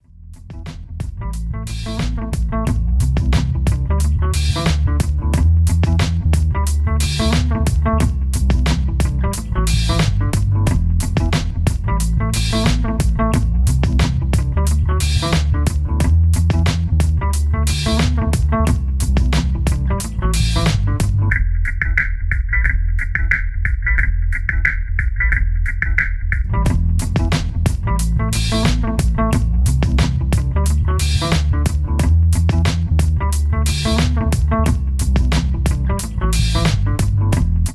(0:37) Some lounge music